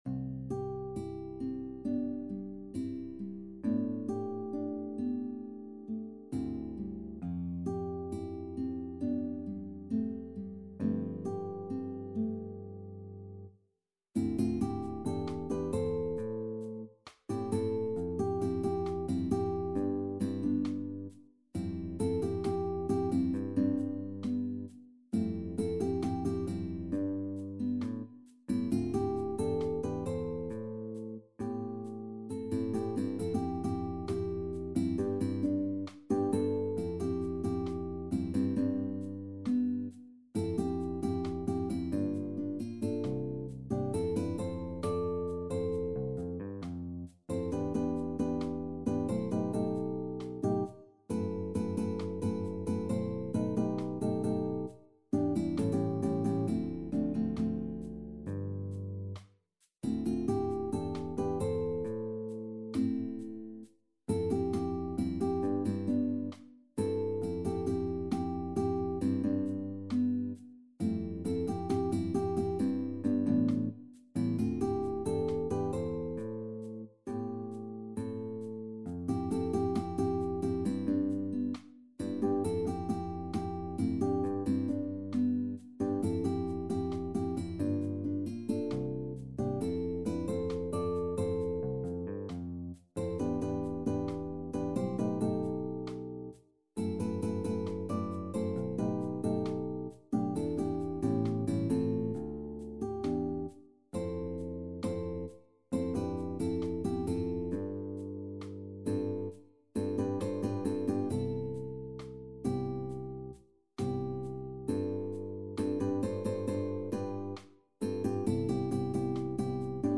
SSAB avec piano | SATB avec piano